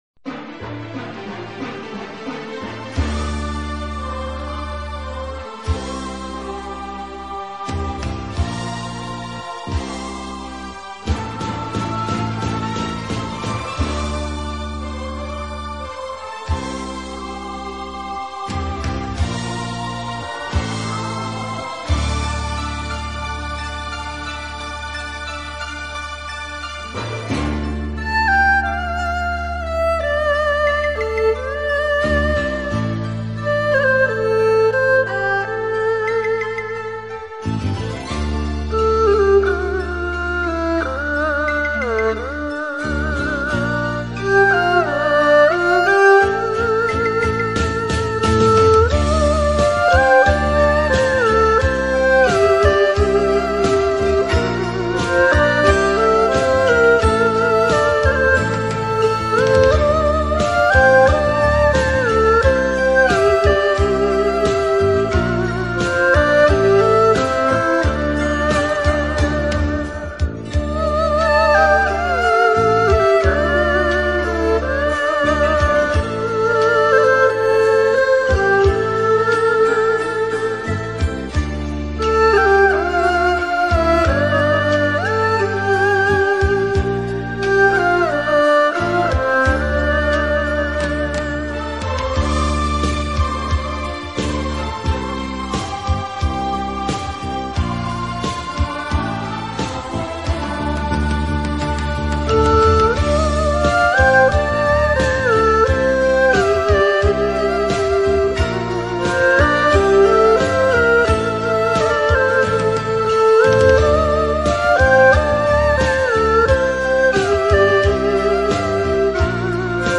古典民乐, 经典配乐 你是第28896个围观者 4条评论 供稿者： 标签：, , , ,